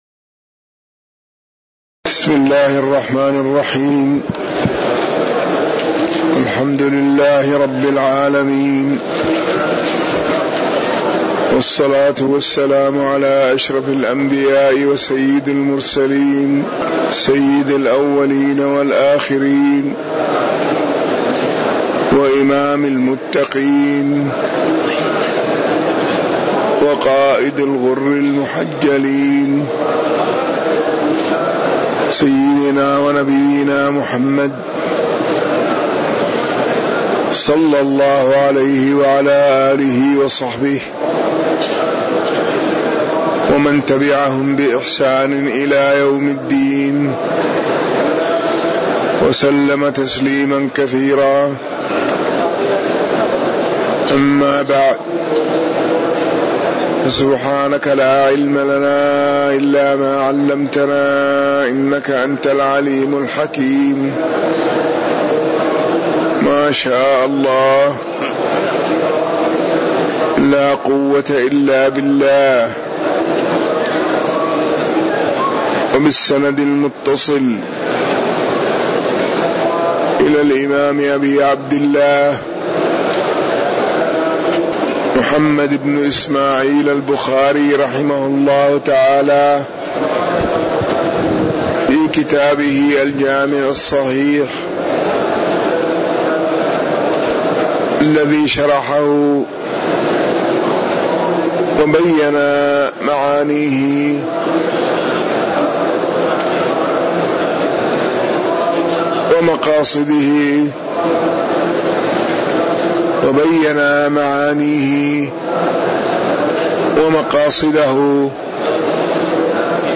تاريخ النشر ٢ ربيع الثاني ١٤٣٩ هـ المكان: المسجد النبوي الشيخ